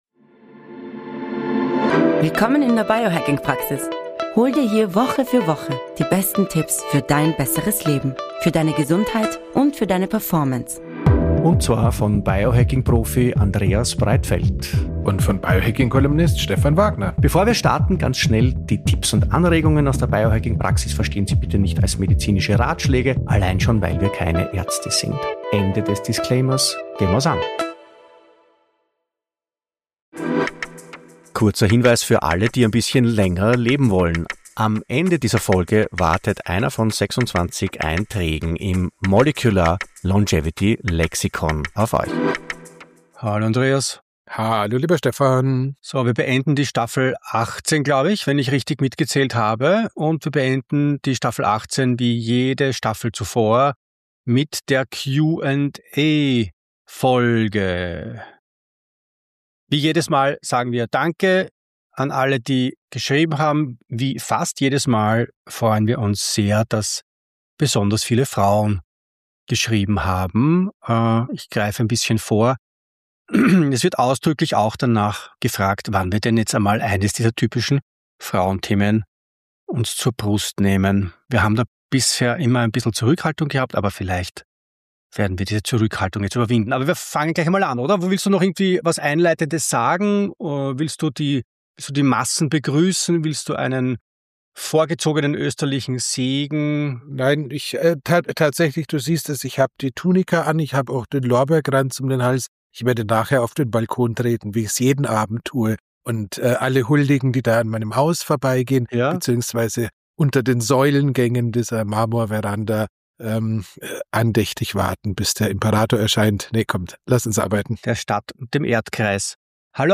Q&A 18